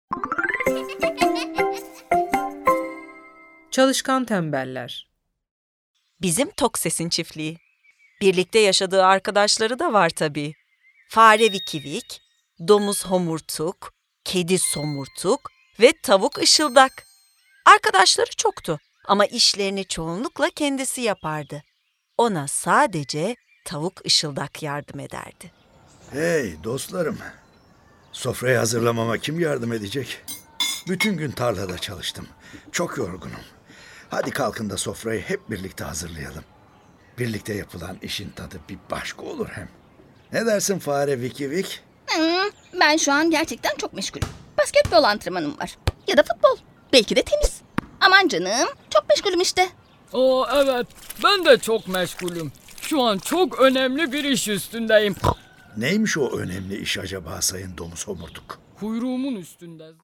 Çalışkan Tembeller (Kırmızı Tavuk Masalı) Tiyatrosu